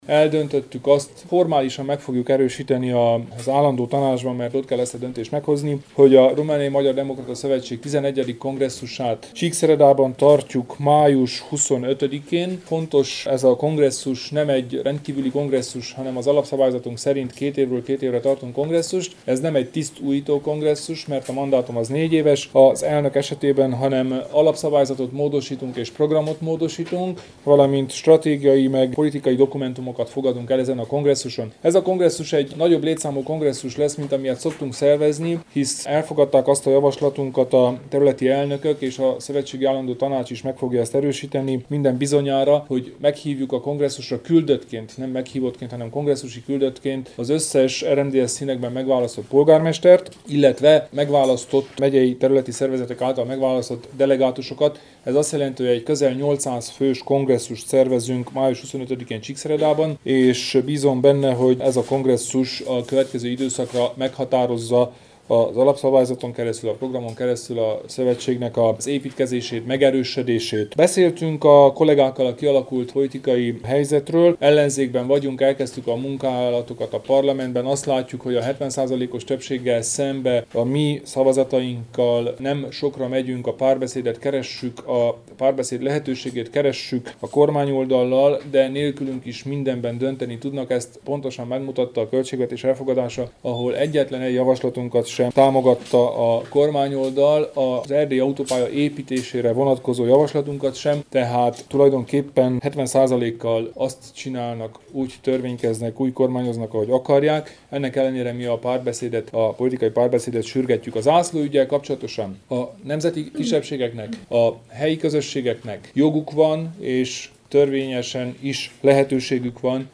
Kelemen Hunor nyilatkozata